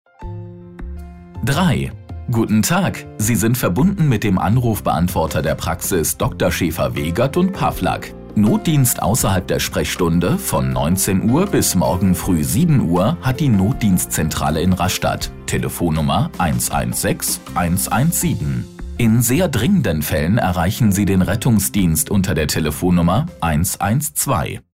IVR Ansage und weiterführende Telefonansagen.
Natürlich mit Gemafreier Musik.
Routing 2 – Info Ansage Praxis